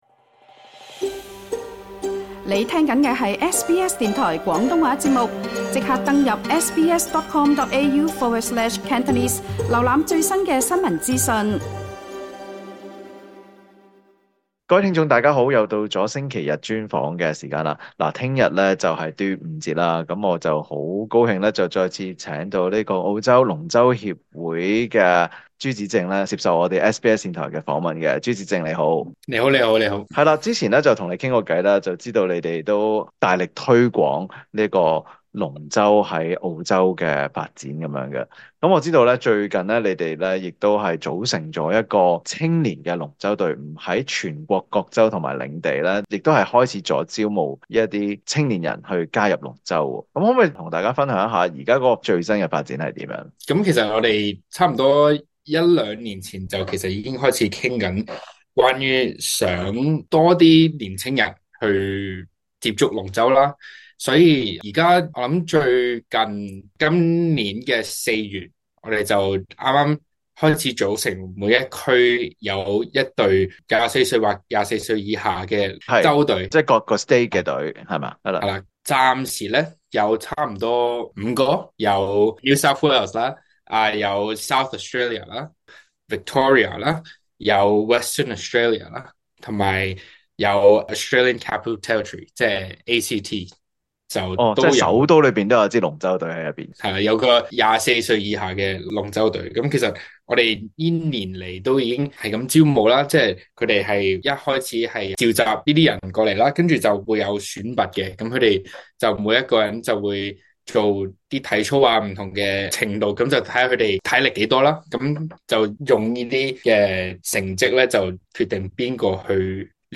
【星期日專訪】